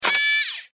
ロボットアーム5.mp3